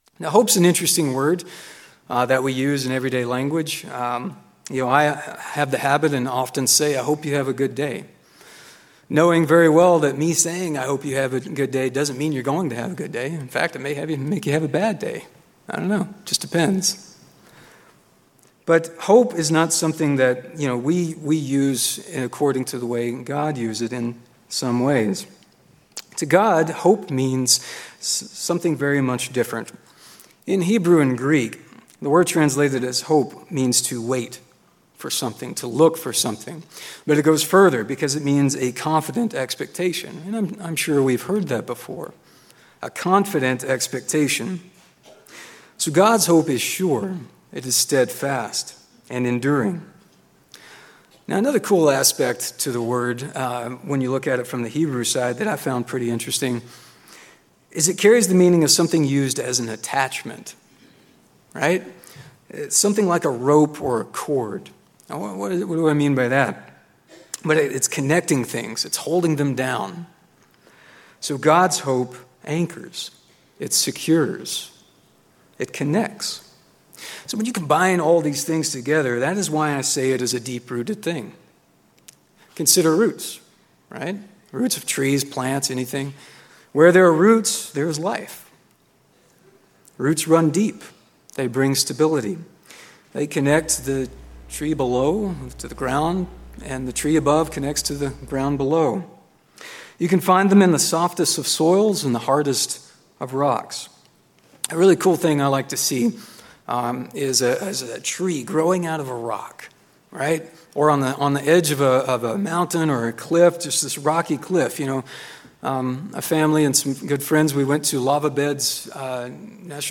Given in Klamath Falls, Oregon